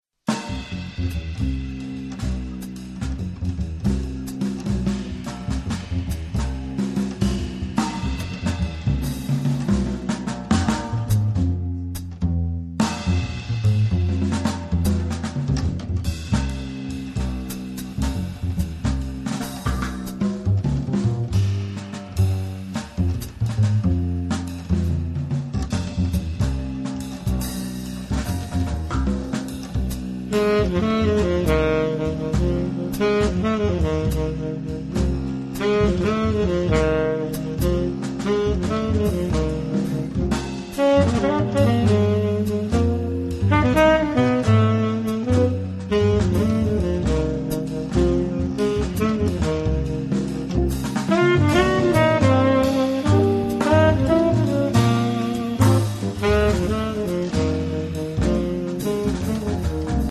blues inusuale e fumoso